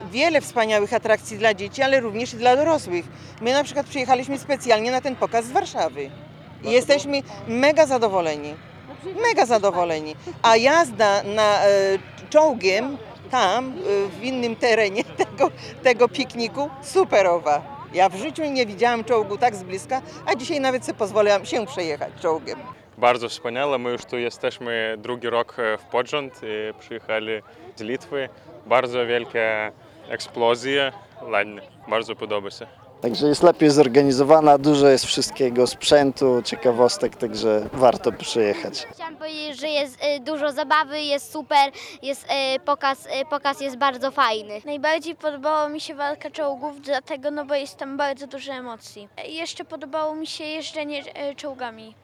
Pomimo kiepskiej pogody i opadów deszczu na poligonie Orzysz zjawiło się tysiące widzów z Polski i z zagranicy.
Jak imprezę oceniają przybyli goście?